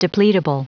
Prononciation du mot depletable en anglais (fichier audio)
Prononciation du mot : depletable